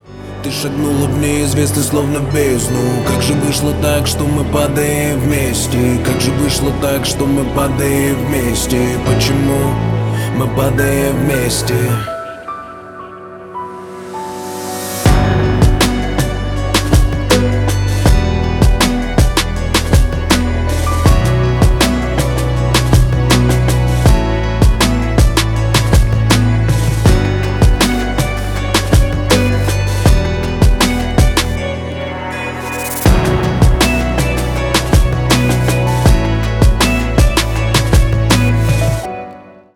Рэп и Хип Хоп
грустные